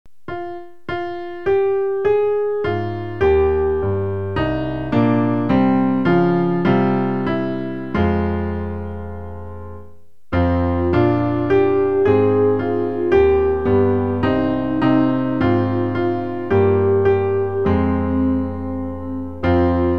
Klavier-Playback zur Begleitung der Gemeinde
MP3 Download (ohne Gesang)
Themenbereich: Advent